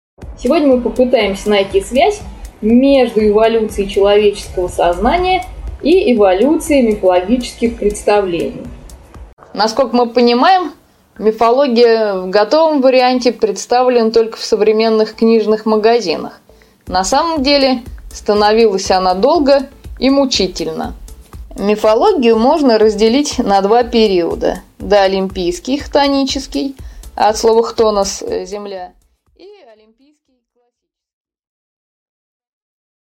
Аудиокнига 1.Доолимпийский и Классический периоды. Формы религии | Библиотека аудиокниг